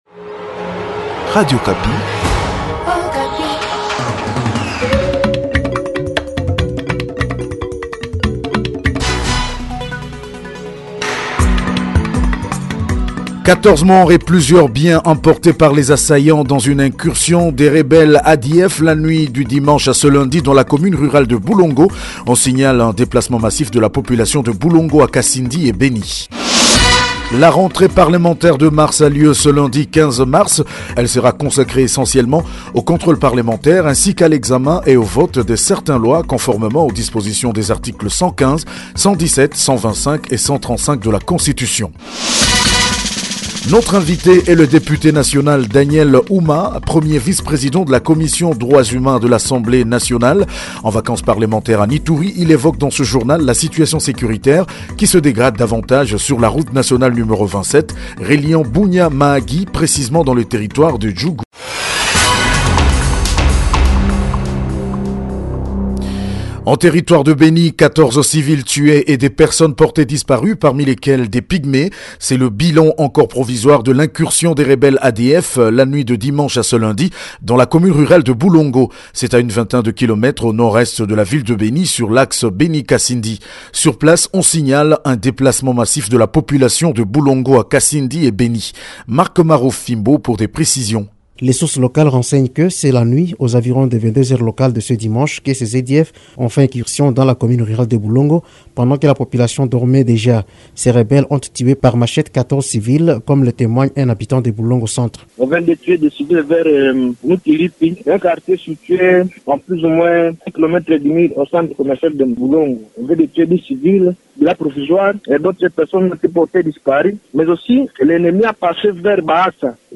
JOURNAL MIDI DU LUNDI 15 MARS 2021